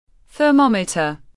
Thermometer /θəˈmɒm.ɪ.tər/